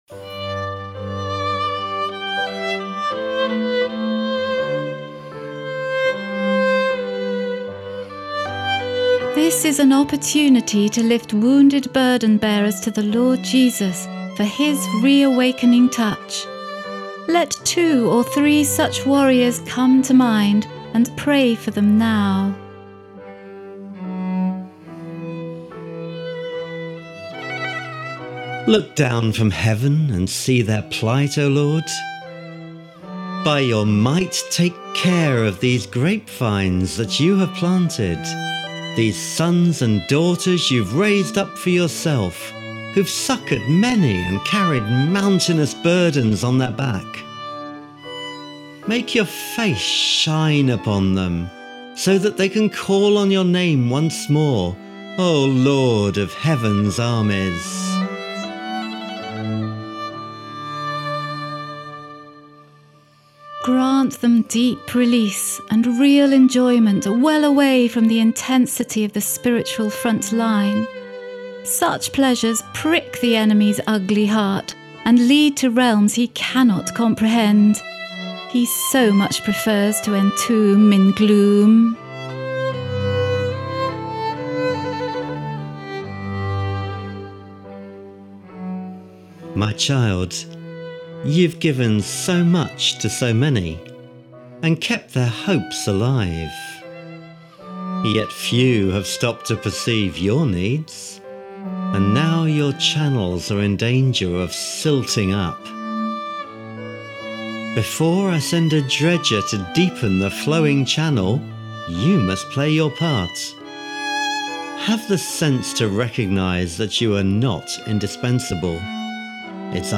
Tartini - Violin sonata in E minor